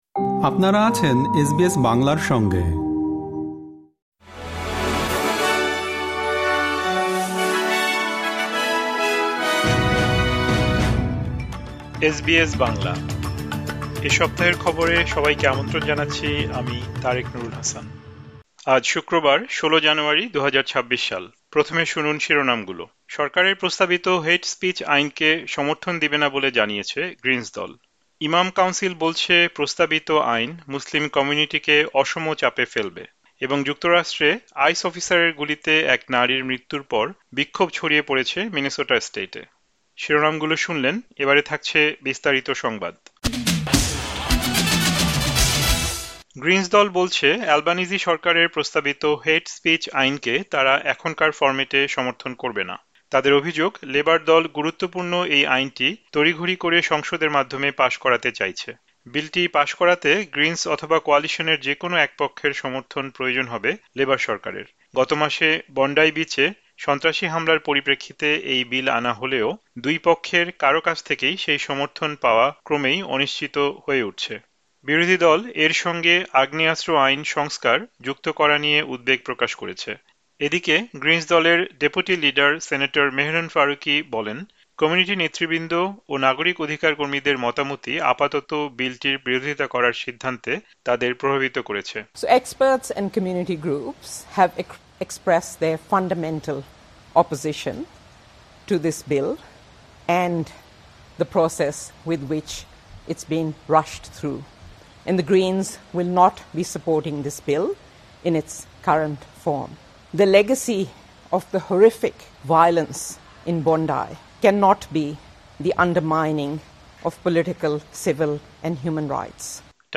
এ সপ্তাহের খবর: যুক্তরাষ্ট্রের মিনেসোটায় এক আইস অফিসারের গুলিতে একজন নারীর মৃত্যুর পর ছড়িয়ে পড়েছে বিক্ষোভ